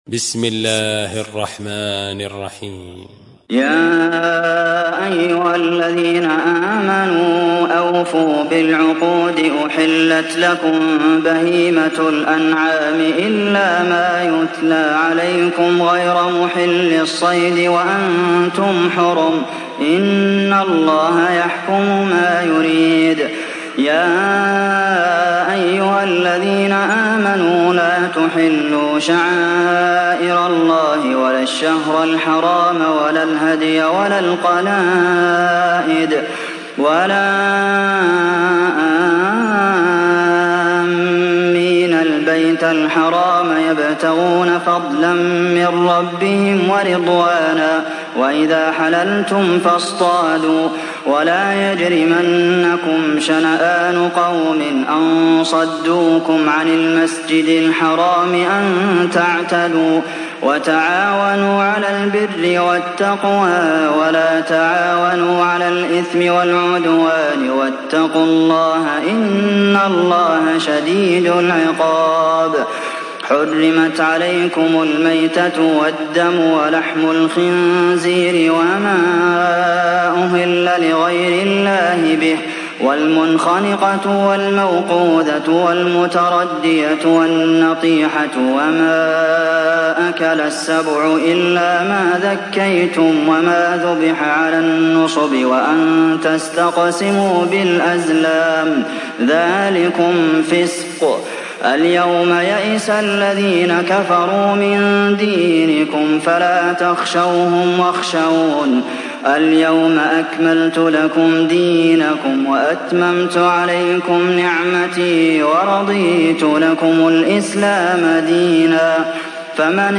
تحميل سورة المائدة mp3 بصوت عبد المحسن القاسم برواية حفص عن عاصم, تحميل استماع القرآن الكريم على الجوال mp3 كاملا بروابط مباشرة وسريعة